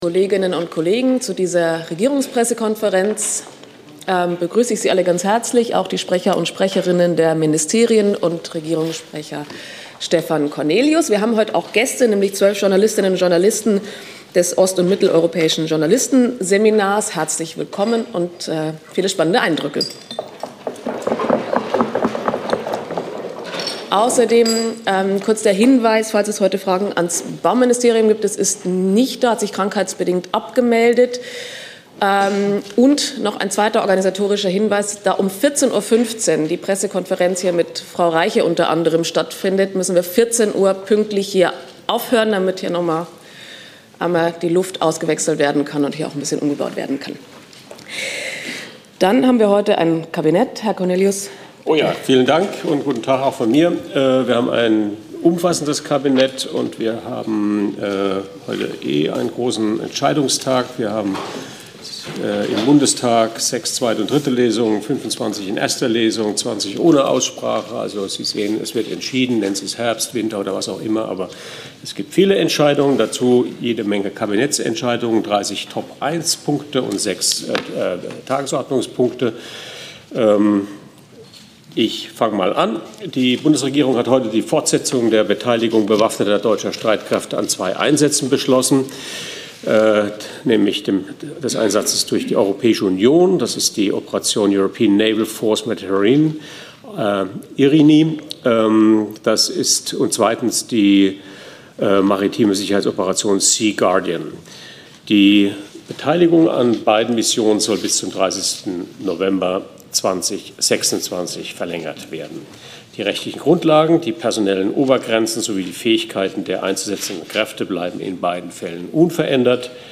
Regierungspressekonferenz in der BPK vom 8. November 2023